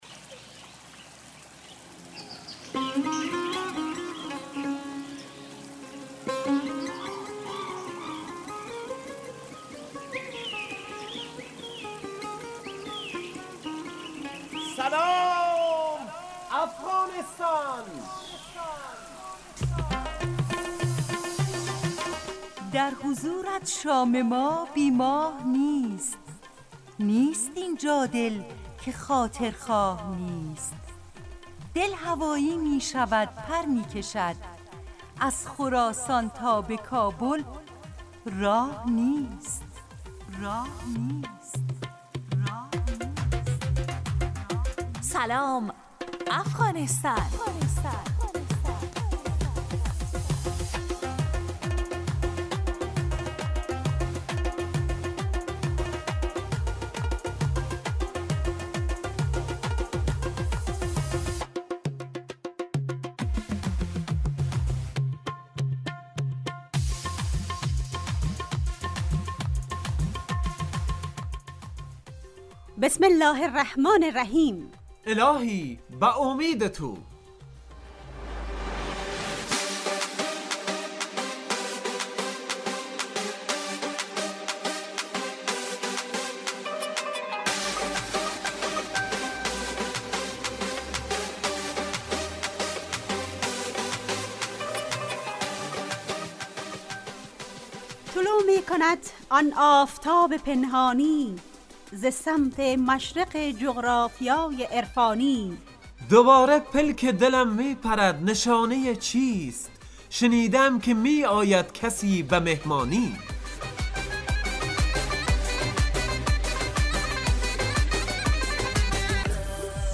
برنامه صبح گاهی رادیو دری به نام سلام افغانستان با موضوع این هفته اغاز و پایان